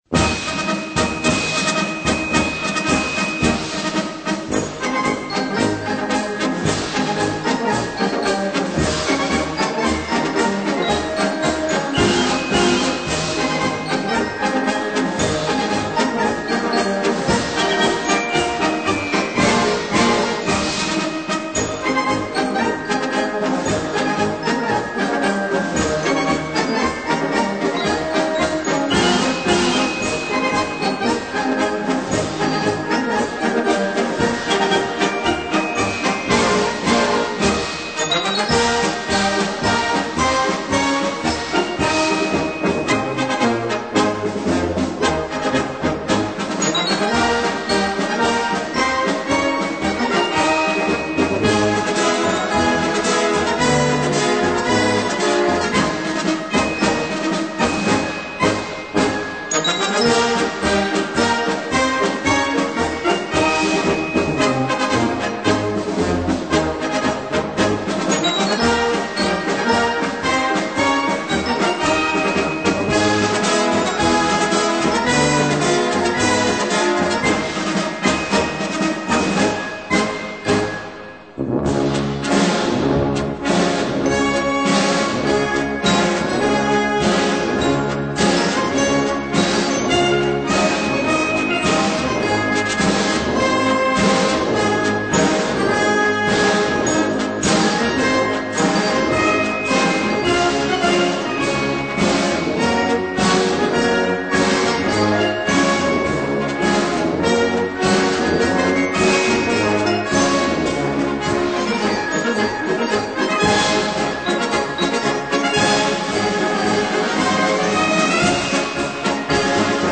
Marschmusik